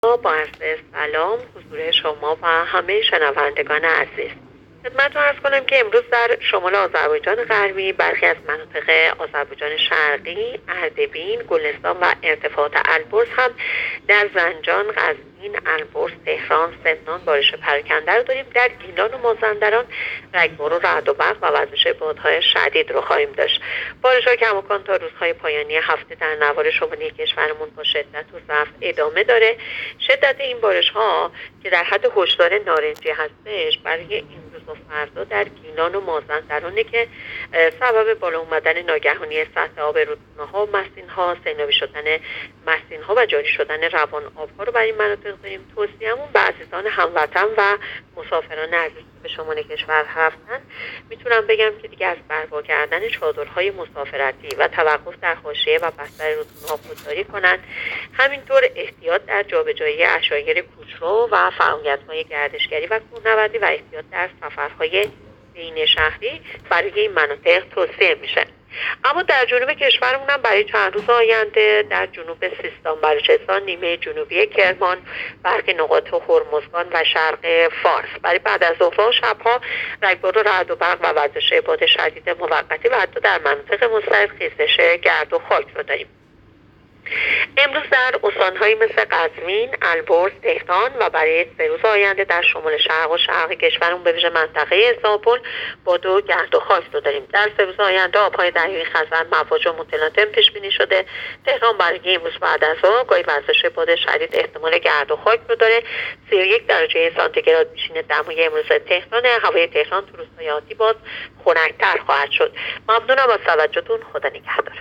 گزارش رادیو اینترنتی پایگاه‌ خبری از آخرین وضعیت آب‌وهوای ۲۳ شهریور؛